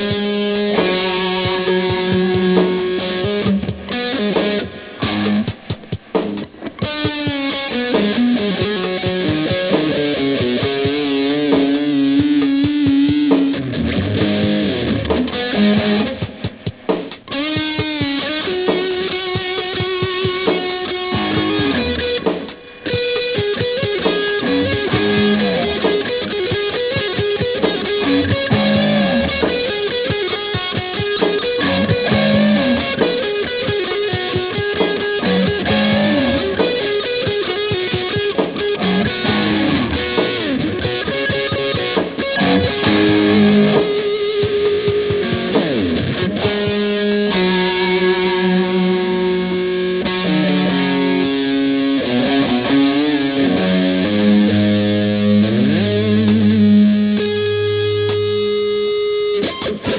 These riffs were recorded in mono to minimize file size.